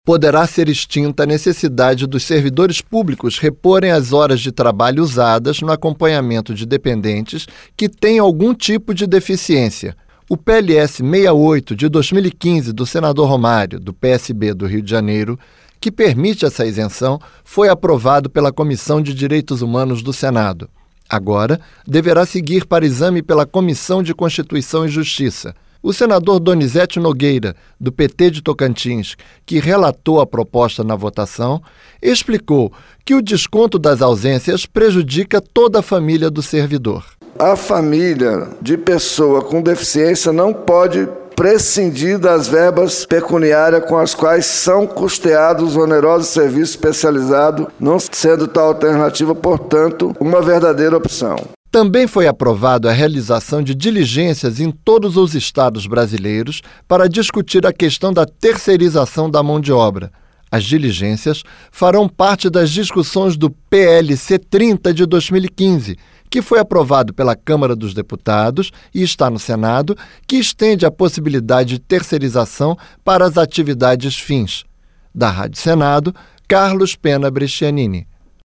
Rádio Senado
O senador Donizeti Nogueira, do PT de Tocantins, que relatou a proposta na votação, explicou que o desconto das ausências prejudica toda a família do servidor.